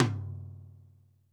RX5 TOM 2.wav